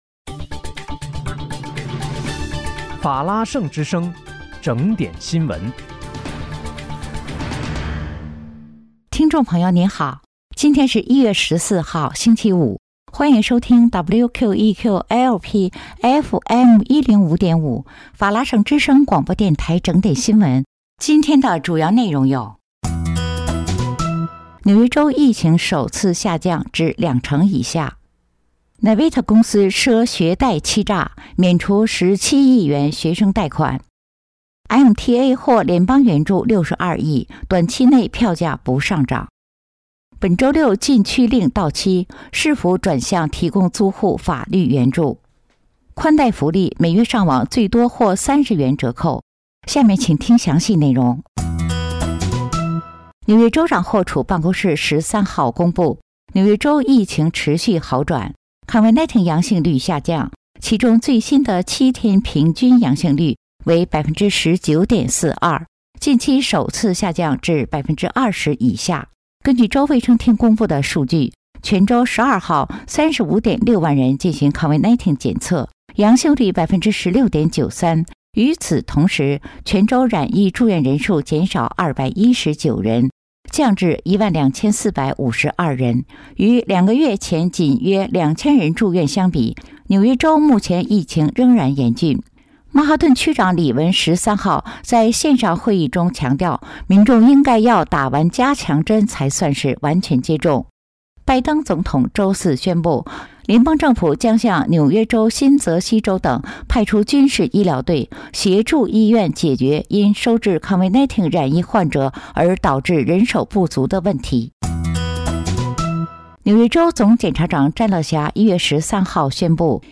1月14日（星期五）纽约整点新闻